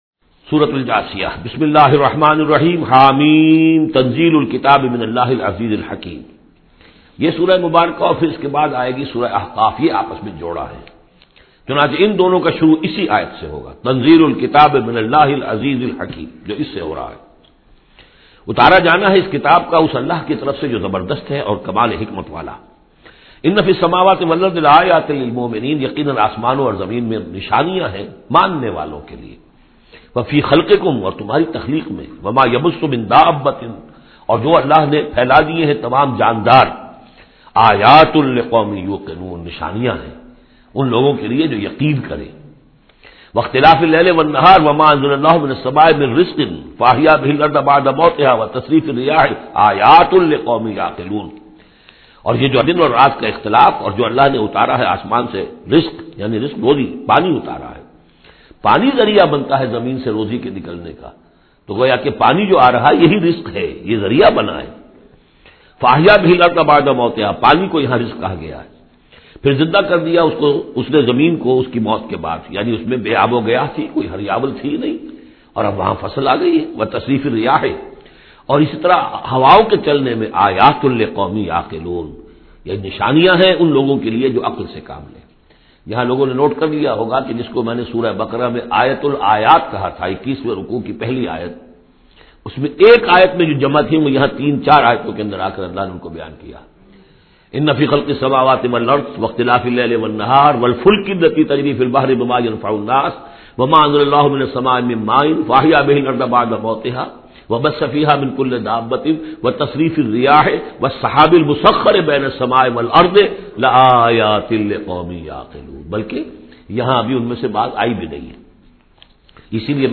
Surah Jathiya Tafseer by Dr Israr Ahmed
Surah Jathiya is 45 Surah of Holy Quran. Listen online mp3 tafseer of Surah Jathiya in the voice of Dr Israr Ahmed Late.